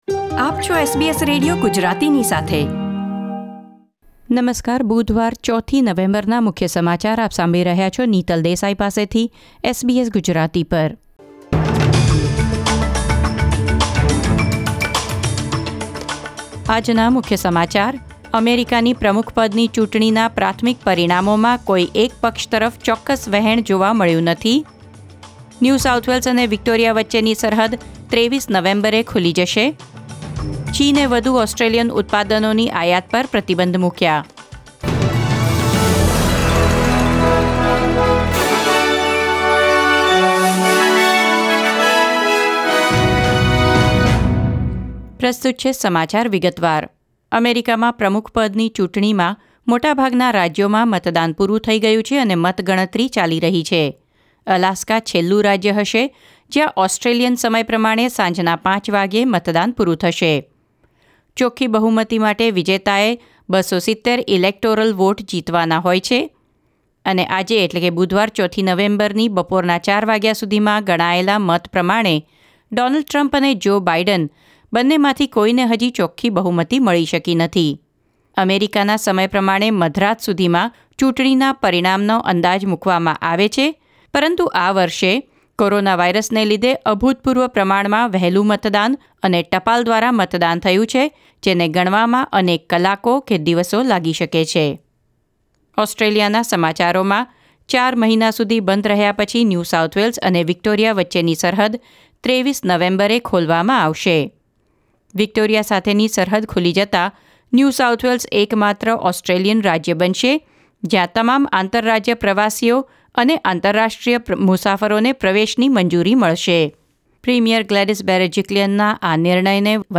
SBS Gujarati News Bulletin 4 November 2020